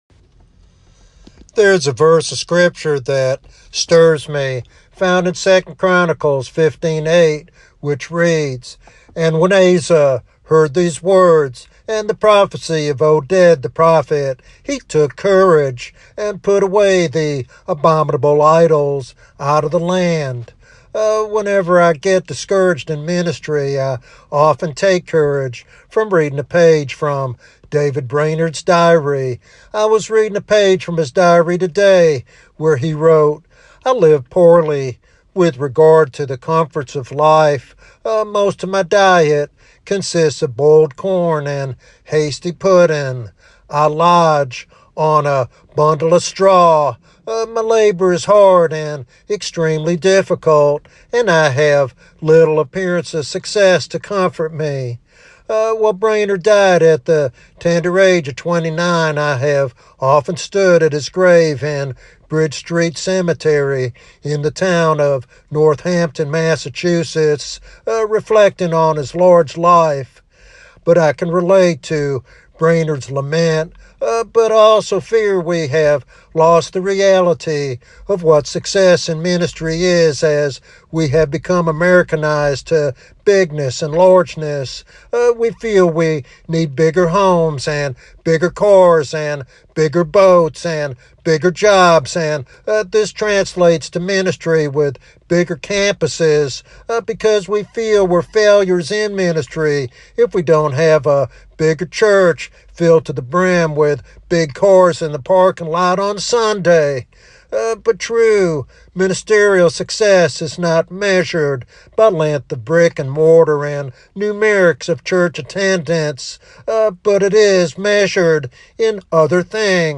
The sermon calls for a return to spiritual authenticity and courage in ministry, reminding listeners that true success is measured by faithfulness, not numbers.